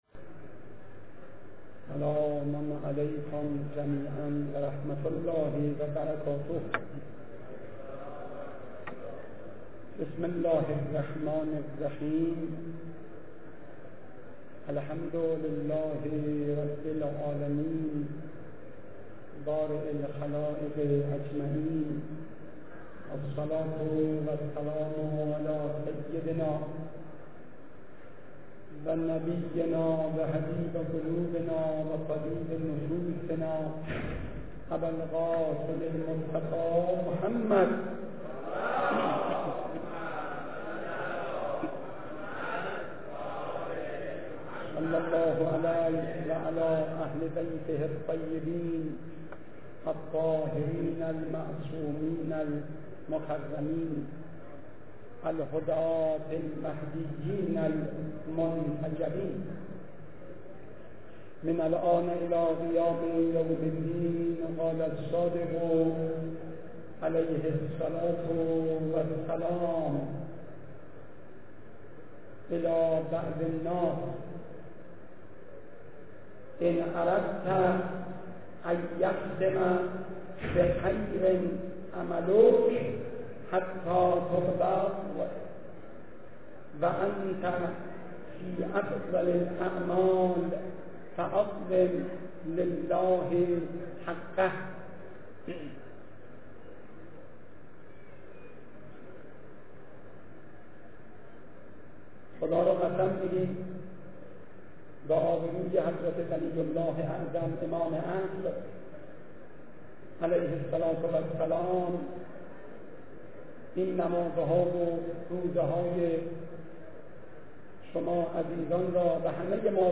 سخنرانی پیش از خطبه - حجةالاسلام رفیعی